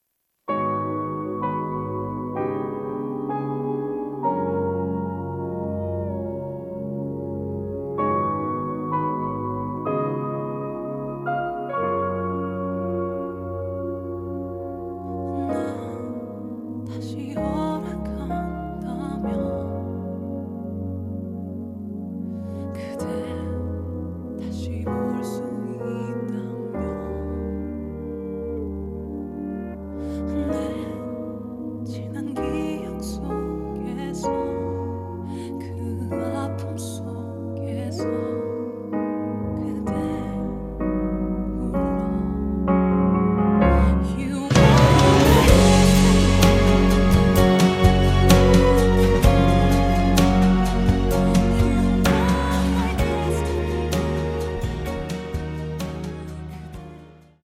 음정 -1키 3:51
장르 가요 구분 Voice Cut